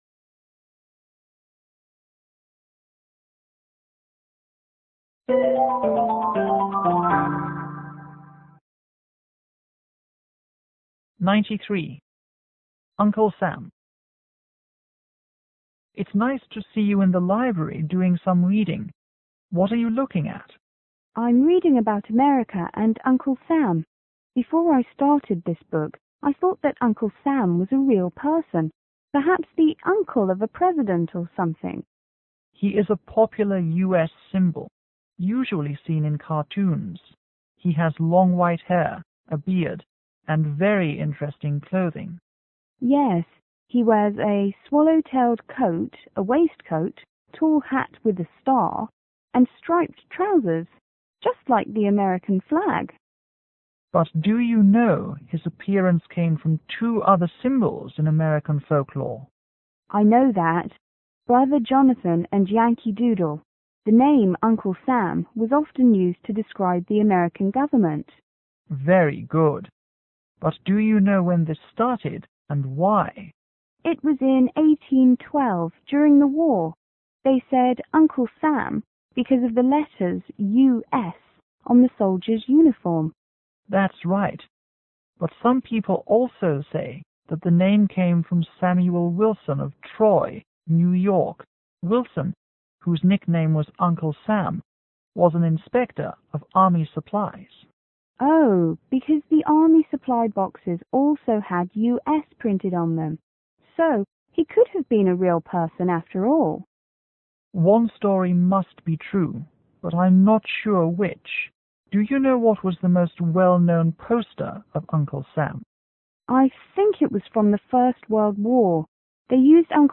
T: Teacher    S: Student